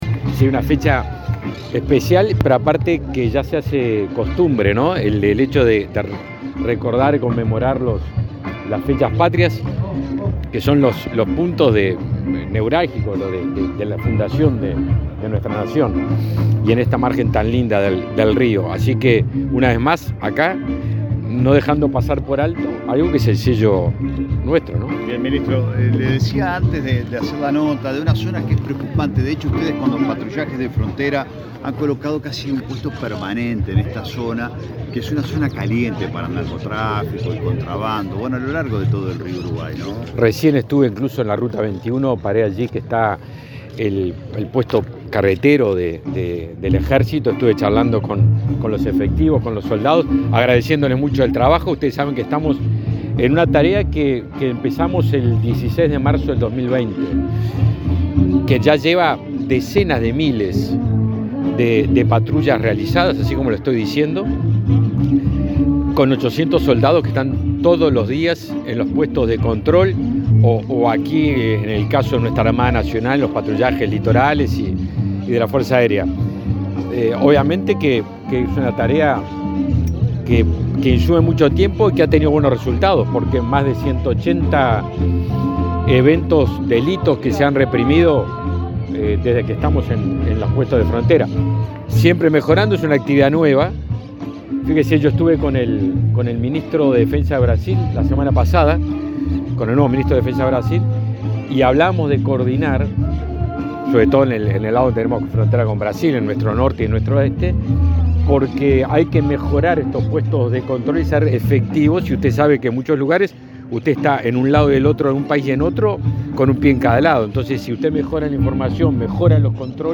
Declaraciones del ministro de Defensa Nacional, Javier García
Antes dialogó con la prensa.